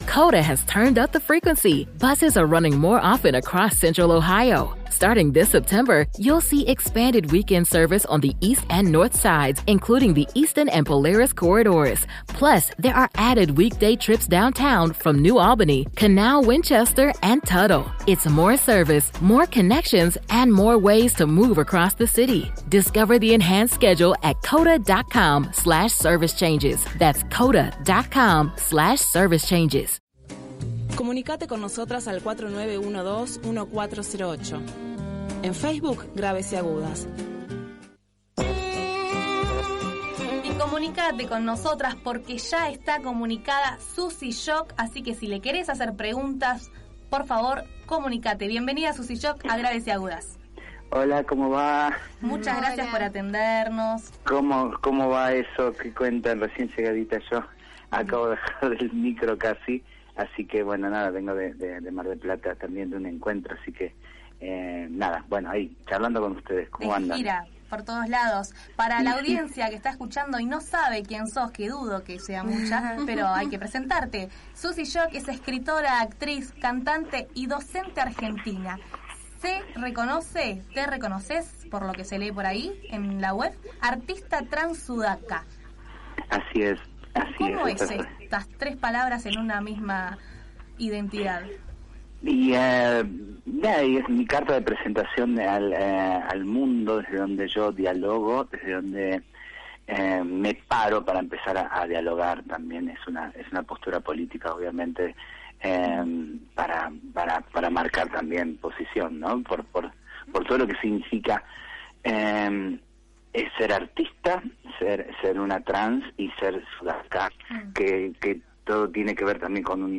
Lo afirma Susy Shock, en comunicación con Graves y agudas donde compartió el recorrido que ha hecho en el arte argentino de los últimos años. Susy es una artista completa: es escritora, actriz, cantante y docente argentina.